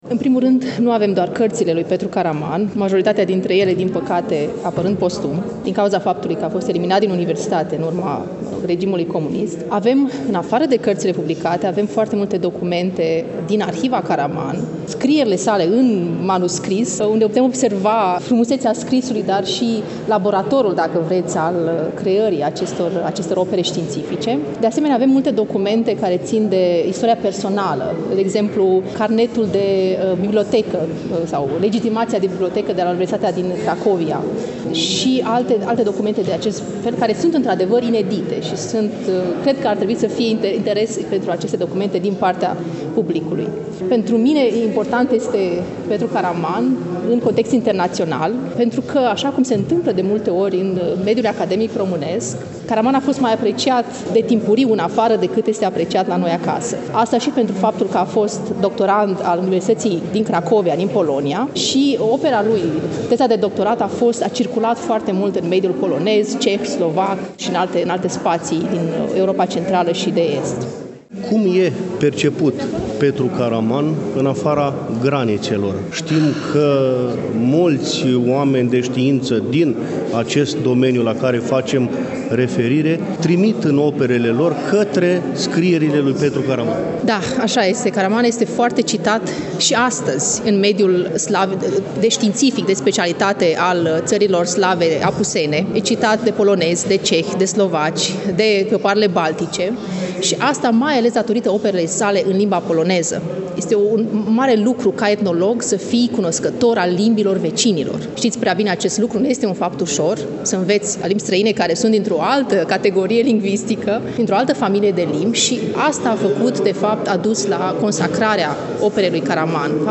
Cu prilejul împlinirii, la 14 decembrie 2023, a 125 de ani de la nașterea cel mai mare etnolog român, la Iași, în Sala „Hasdeu” din incinta BCU „Mihai Eminescu”, a fost organizat un eveniment de înaltă ținută academică.